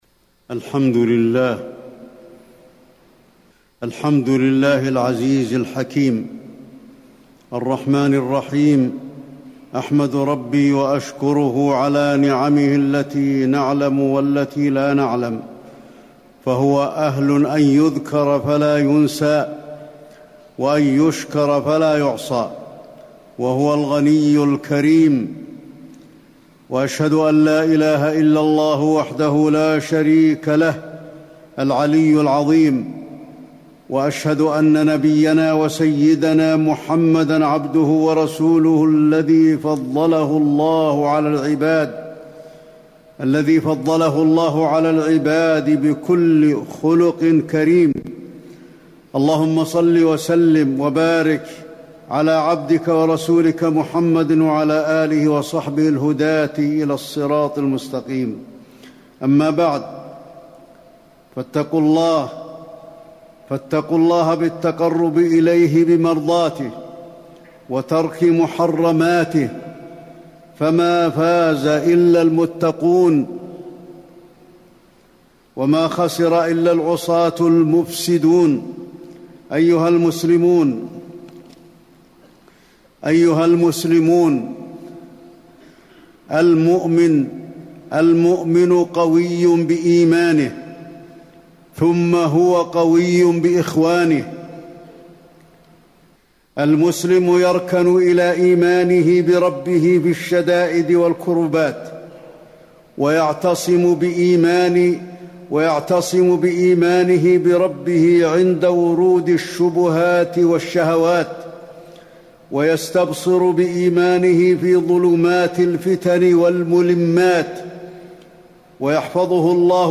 تاريخ النشر ١٤ ذو القعدة ١٤٣٩ هـ المكان: المسجد النبوي الشيخ: فضيلة الشيخ د. علي بن عبدالرحمن الحذيفي فضيلة الشيخ د. علي بن عبدالرحمن الحذيفي أخوة الإسلام